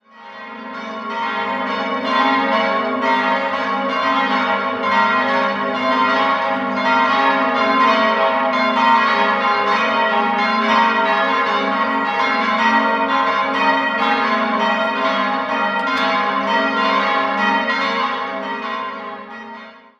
Im Seitenschiff der ehemaligen Kirche wird eine Kapelle entstehen, die das Patronat des Heiligen Heinrich weiterträgt. 4-stimmiges Geläute: as'-b'-c''-des'' Die Glocken wurden 1967/68 von der Gießerei Mabilon gegossen.